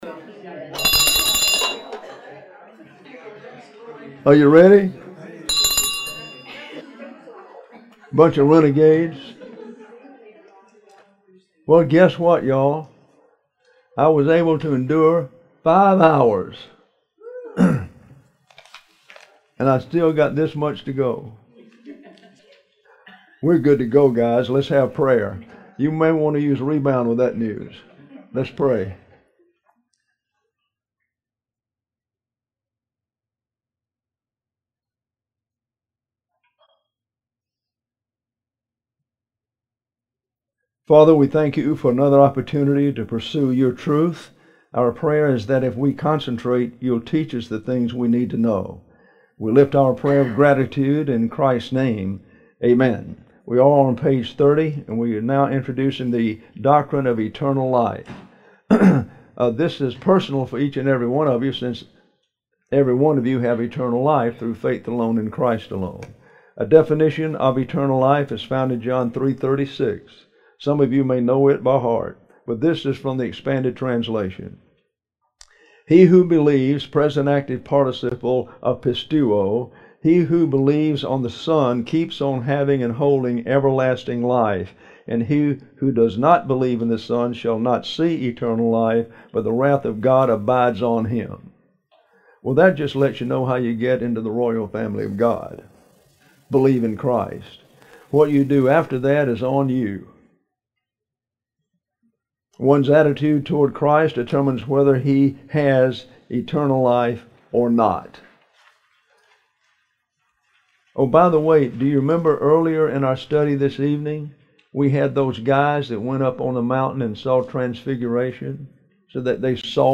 2023 Shreveport Bible Conference: Lesson 6: Doctrine of Eternal Life;